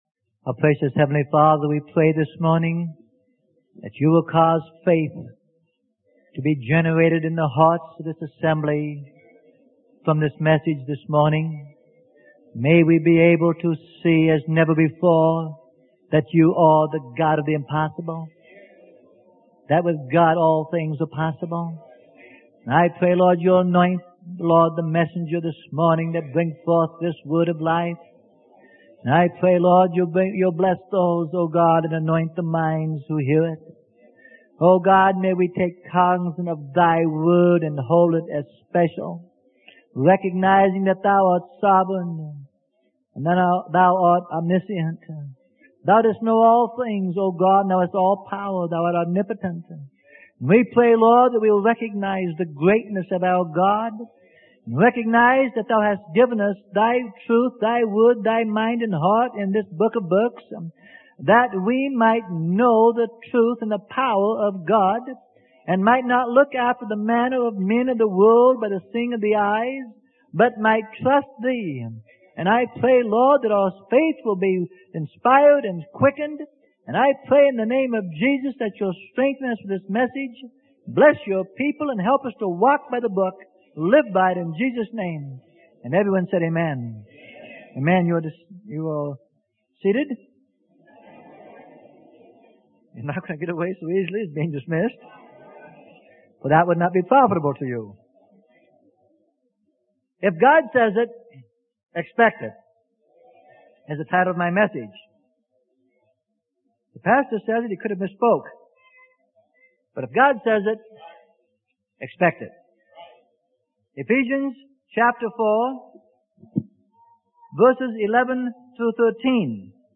Sermon: If God Says It Expect It - Freely Given Online Library